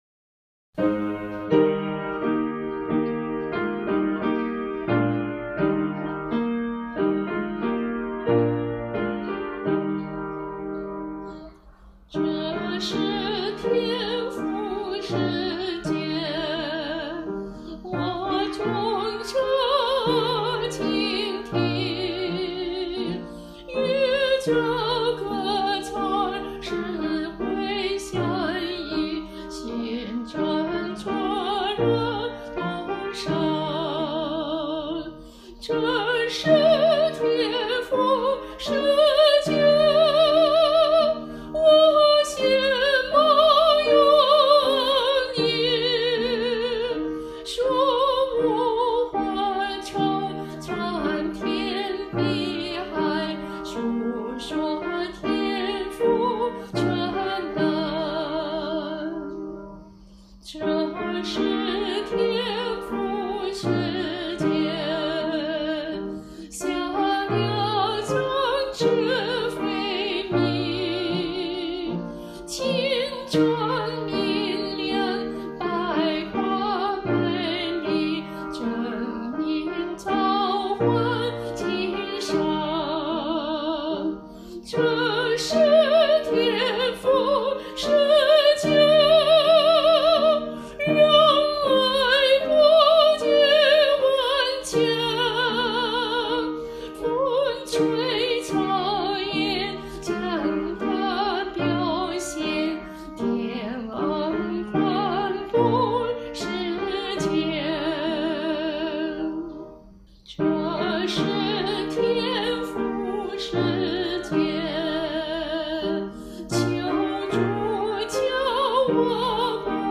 伴奏
旋律朴素流畅，节奏明快，唱起来倍感亲切，令人处身于“树木花草，苍天碧海”，“述说天父全能”的美好意境之中。